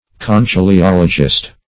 Search Result for " conchyliologist" : The Collaborative International Dictionary of English v.0.48: Conchyliologist \Con*chyl`i*ol"o*gist\, n., Conchyliology \Con*chyl`i*ol"o*gy\, n. See Conchologist , and Conchology .
conchyliologist.mp3